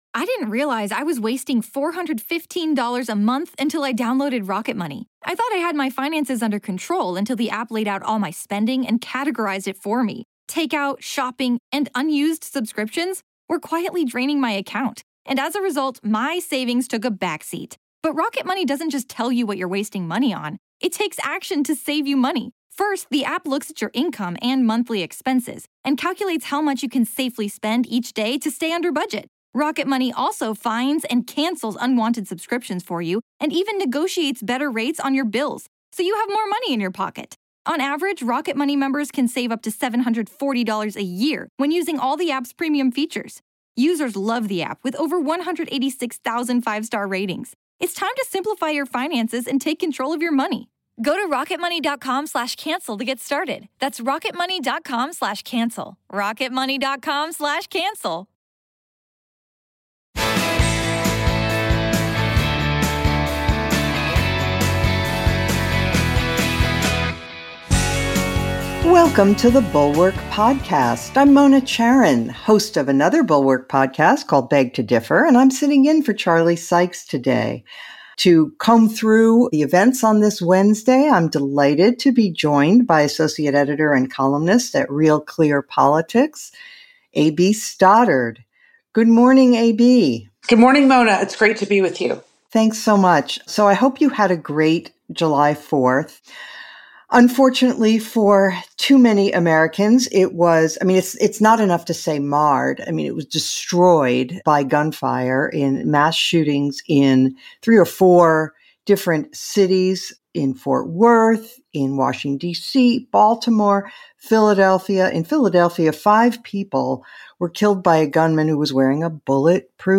House Republicans are racing to the crazy again as they try to nail down who they're going to impeach. Plus, gaming out why Biden gets so little credit for the economy, and the GOP presidential debate stage may end up quite small. A.B. Stoddard joins guest host Mona Charen.